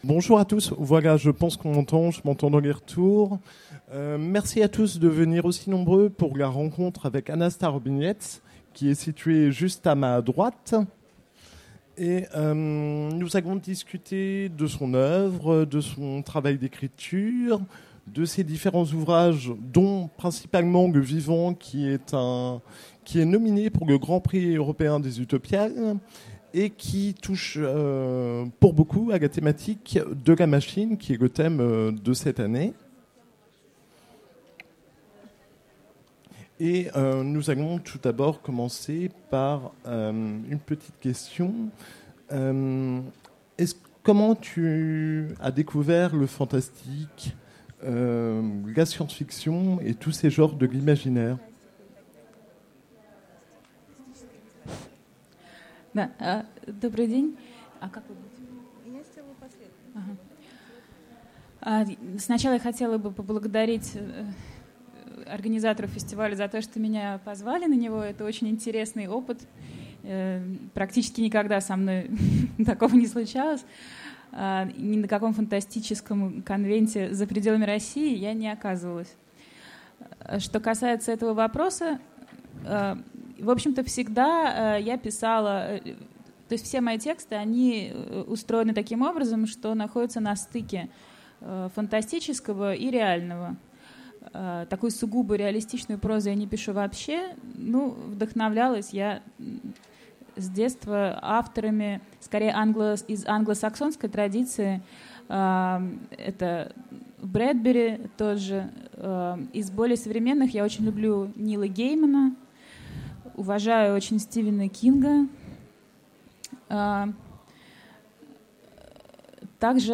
- le 31/10/2017 Partager Commenter Utopiales 2016 : Rencontre avec Anna Starobinets Télécharger le MP3 à lire aussi Anna Starobinets Genres / Mots-clés Rencontre avec un auteur Conférence Partager cet article